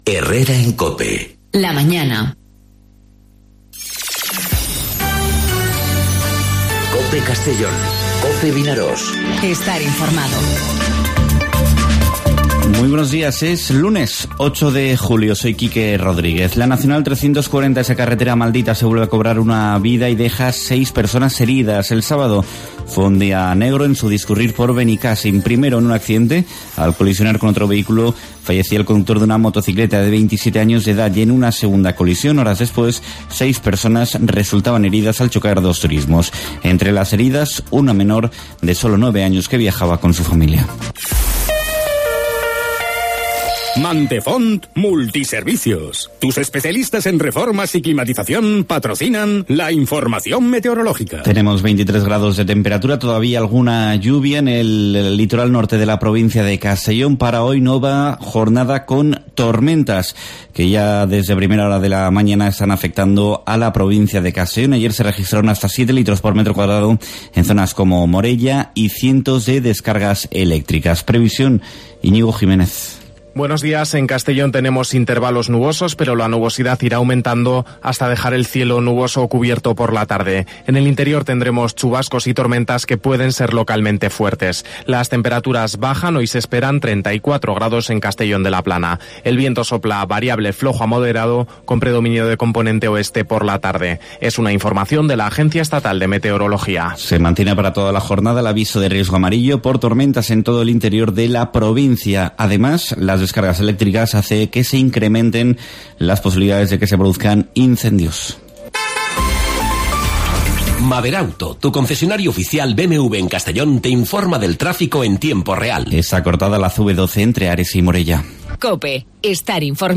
Informativo 'Herrera en COPE' Castellón (08/07/2019)